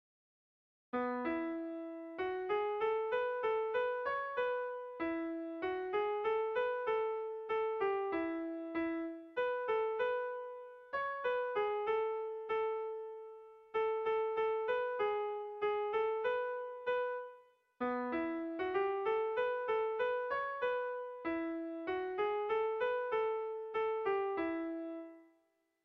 Erromantzea
ABA